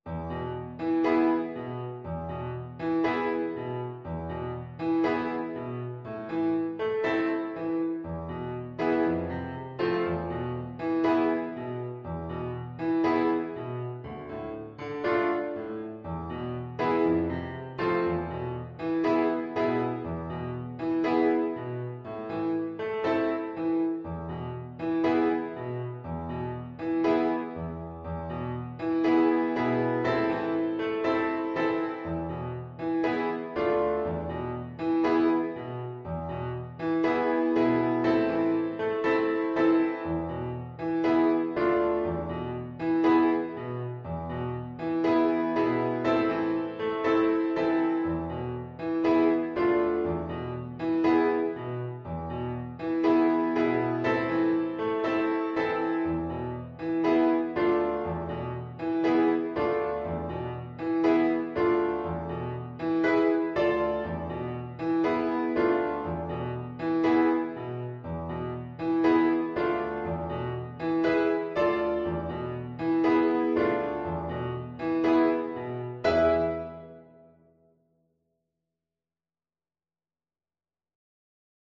Play (or use space bar on your keyboard) Pause Music Playalong - Piano Accompaniment Playalong Band Accompaniment not yet available transpose reset tempo print settings full screen
4/4 (View more 4/4 Music)
A minor (Sounding Pitch) (View more A minor Music for Flute )
Allegro moderato =120 (View more music marked Allegro)